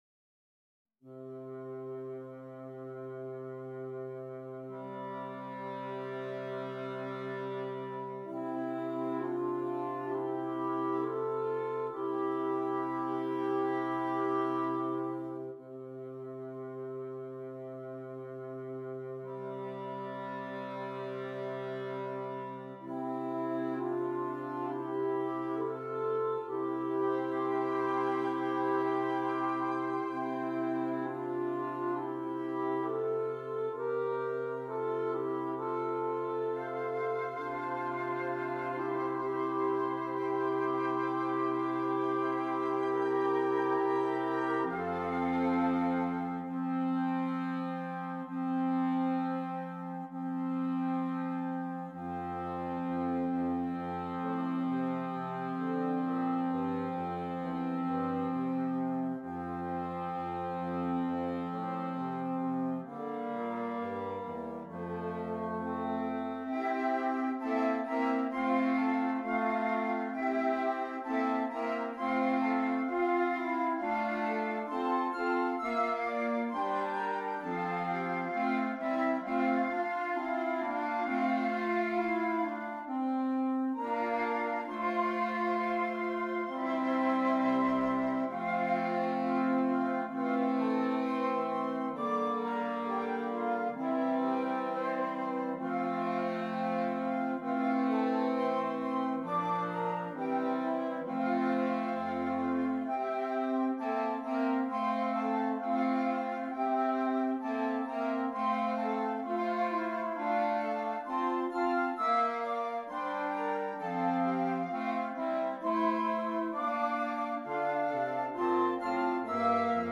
Interchangeable Woodwind Ensemble
Traditional English Folk Song